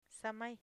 samay air Part of Speech noun Acquisition Method Elicitations Etymology Quichua Phonological Representation 'samai air aire [Spanish] samay [Quichua] (Part of) Synonym (for) aire Example 2231: Pulmoncuna samayta cogin.